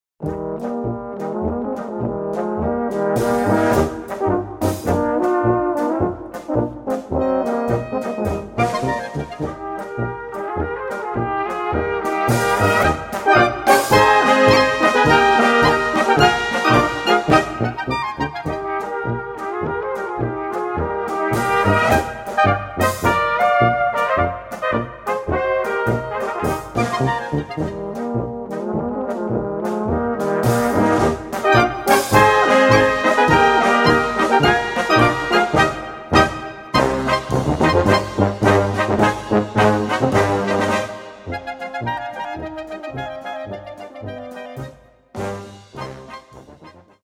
Eine neue Böhmische Polka
Gattung: Polka Besetzung: Blasorchester